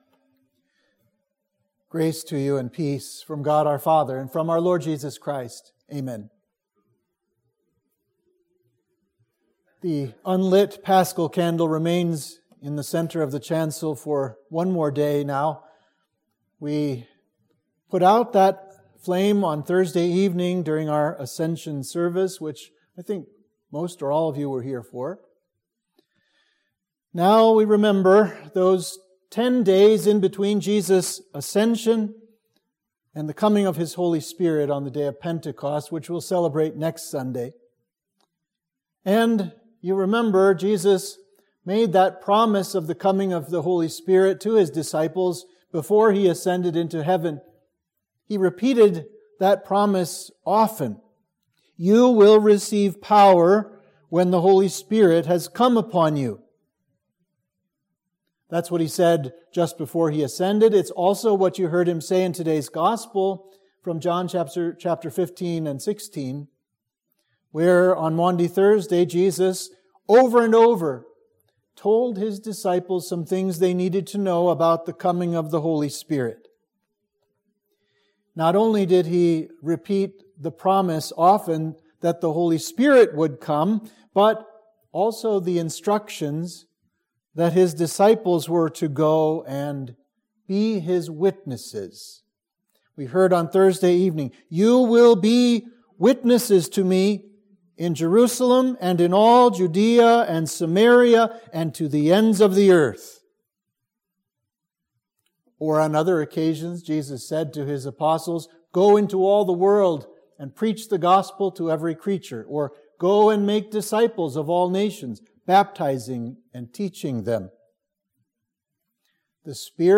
Sermon for the Sunday after Ascension